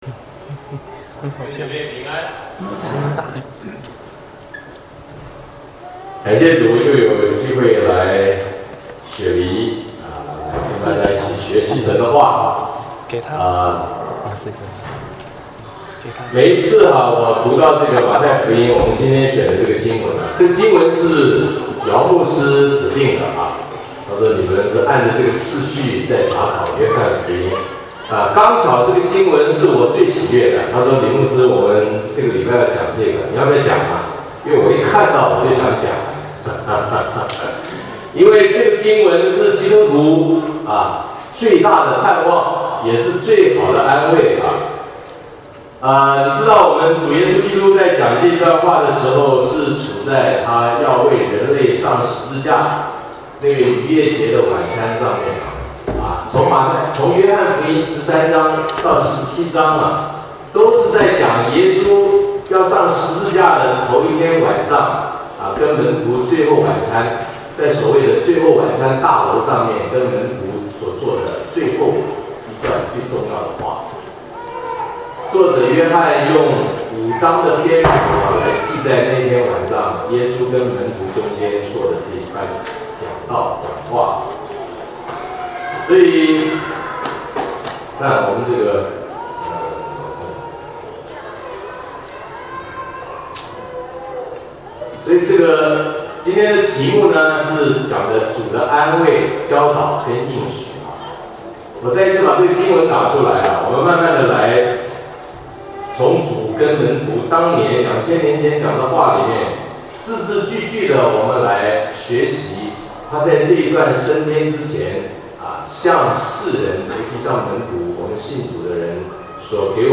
國語堂講道